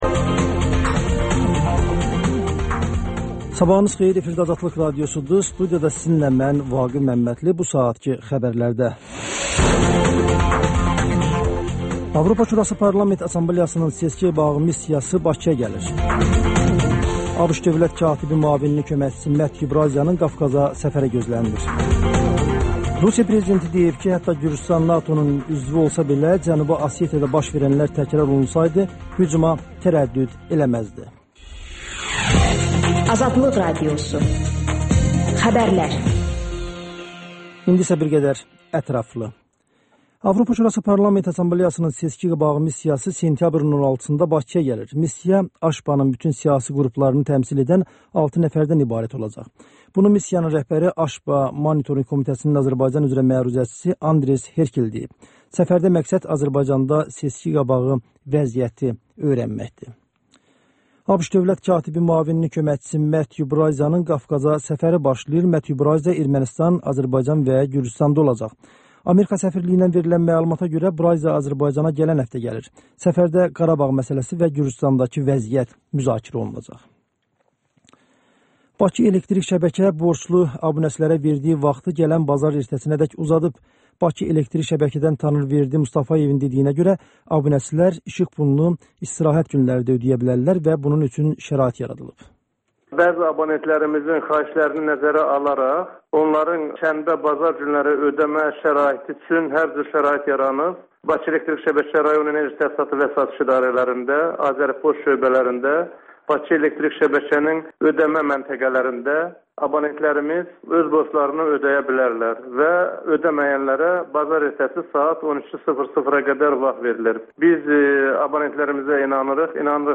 Xəbərlər, müsahibələr, hadisələrin müzakirəsi, təhlillər, sonda HƏMYERLİ rubrikası: Xaricdə yaşayan azərbaycanlılar haqda veriliş